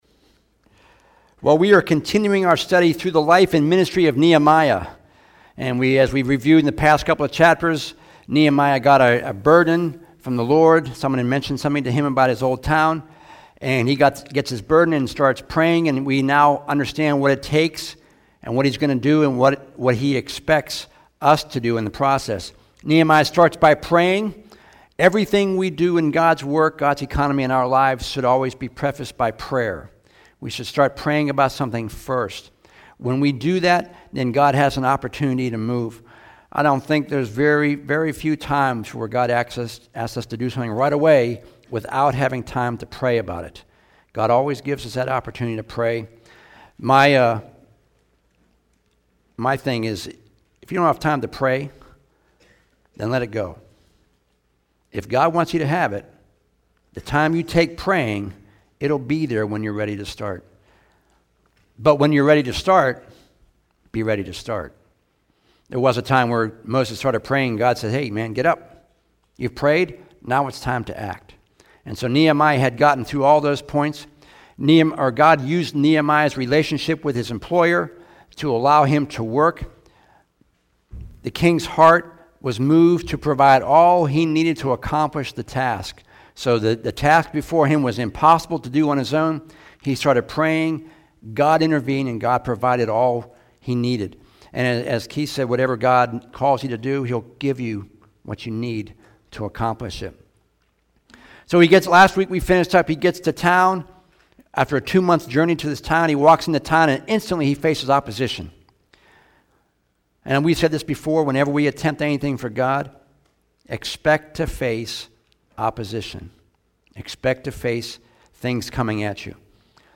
After getting others on board, Nehemiah began working to rebuild the wall, as well as the gates within it. This week's sermon talked about the meaning of each of the gates and the process of going through them, which mirrors the process we typically go through as Christians, after being saved and entering through the